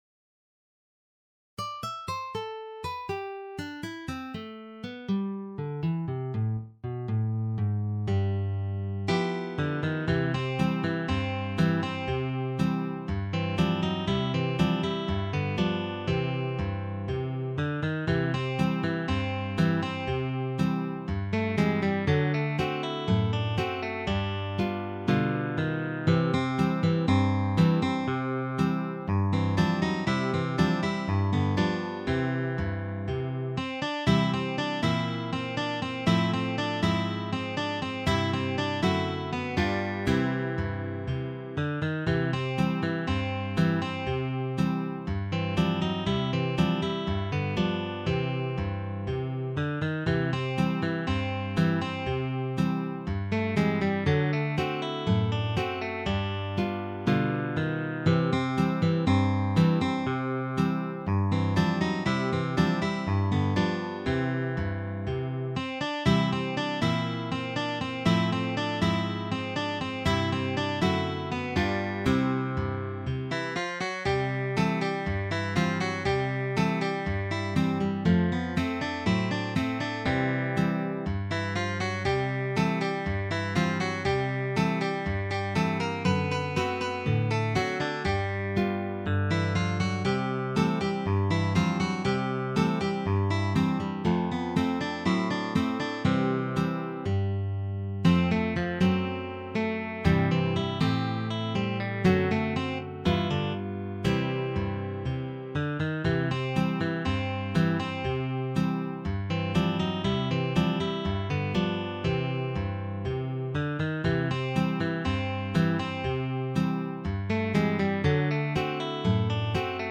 Arrangement für 4 Gitarren